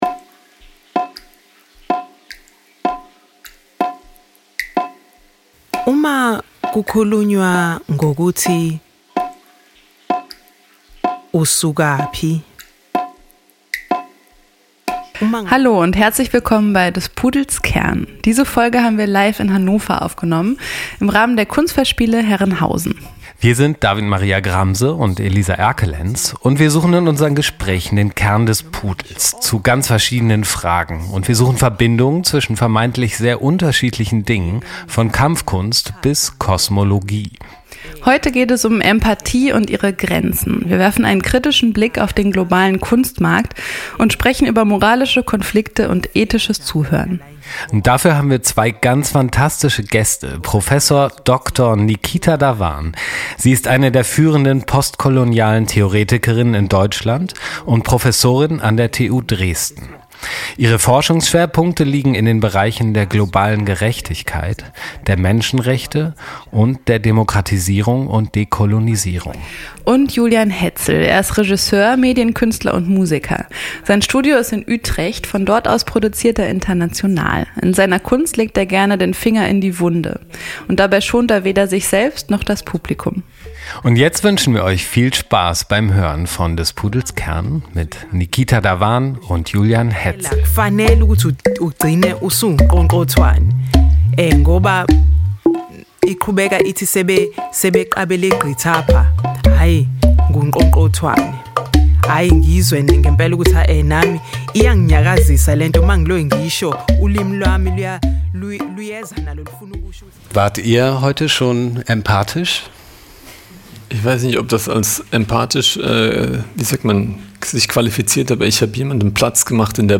Mai 2024 Das Gespräch wurde als Live-Podcast in Hannover aufgenommen, im Rahmen der Kunstfestspiele Herrenhausen.